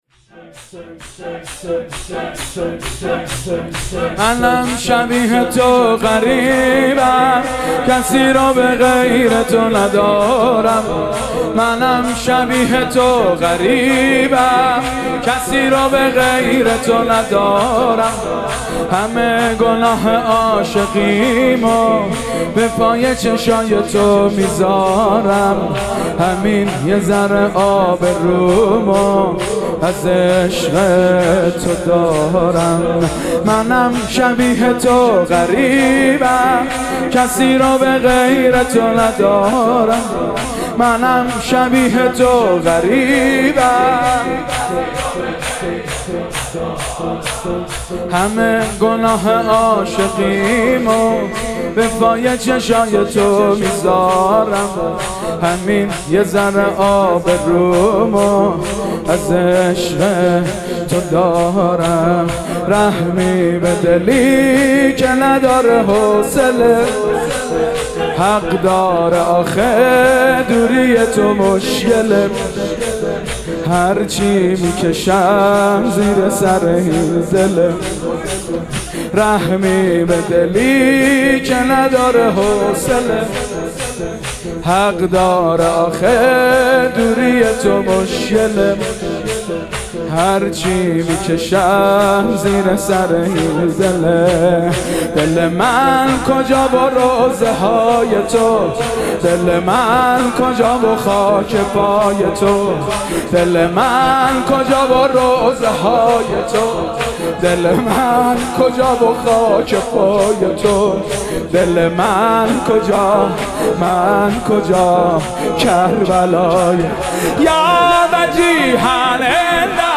مدیحه سرایی
درحسینیه محبان اهل بیت(ع)برگزار گردید.